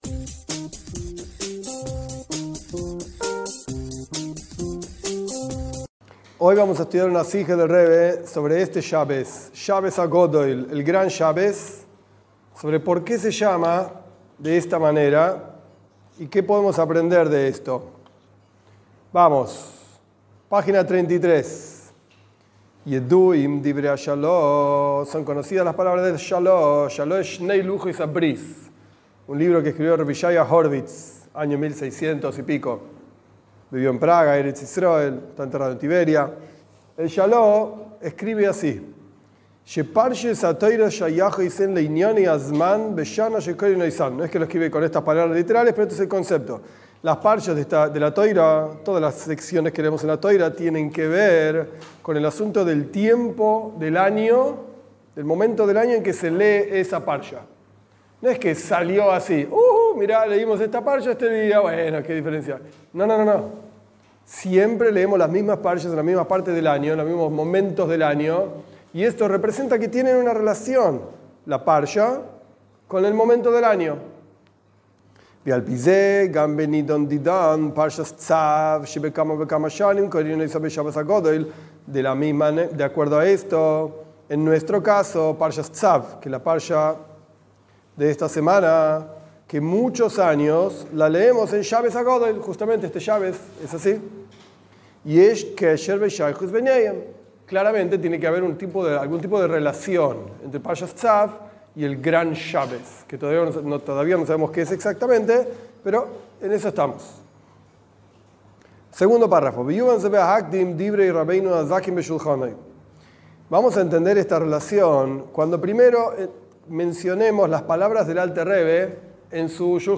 Esta clase es una charla del Rebe, Rabí Menajem Mendel Schneerson sobre el Shabat anterior a Pesaj, que se llama el Gran Shabat.